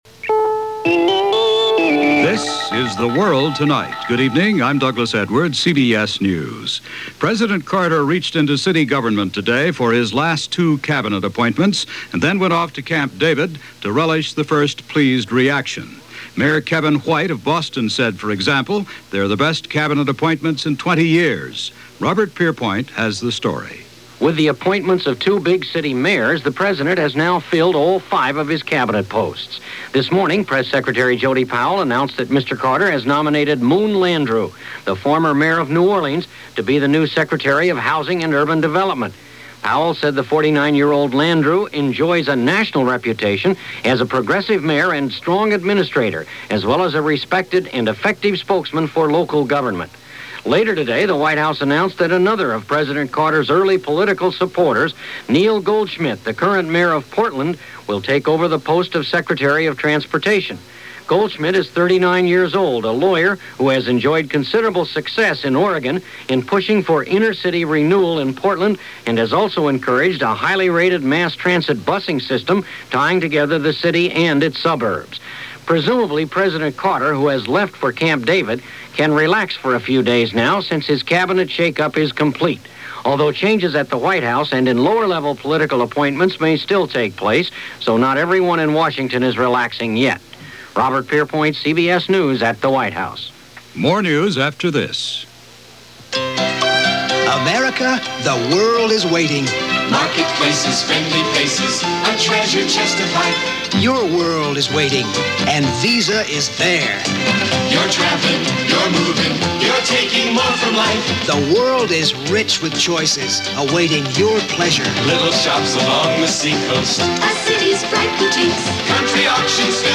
And that’s how it went – this July 27, 1979 as reported on The World Tonight from CBS News.